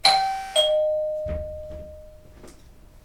dingdong4
bell door house sound effect free sound royalty free Sound Effects